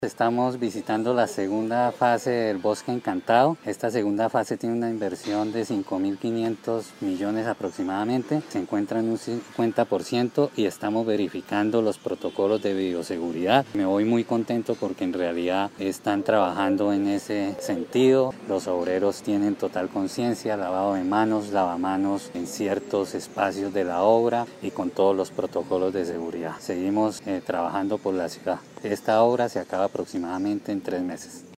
Iván Vargas, secretario de Infraestructura de Bucaramanga
Iván-Vargas-secretario-de-Infraestructura-Bosque-Encantado.mp3